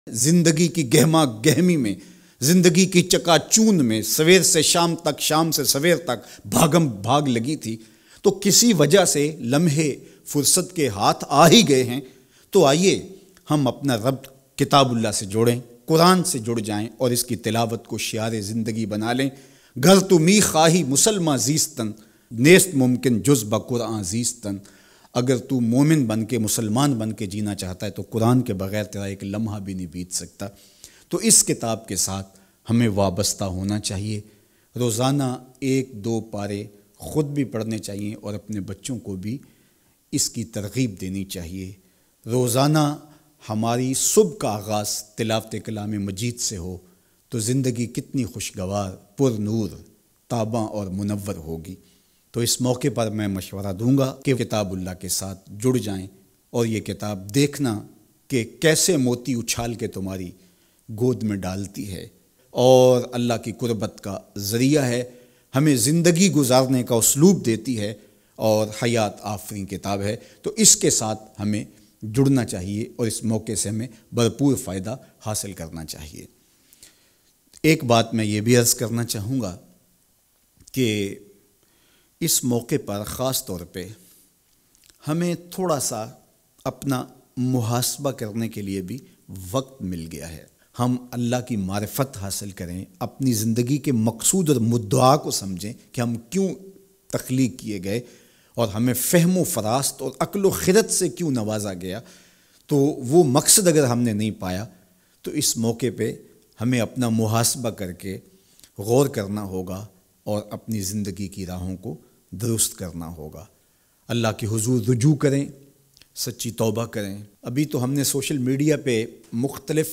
Lockdown Ka Faida uthaaye Muhammad Raza Saqib Mustafai Bayan MP3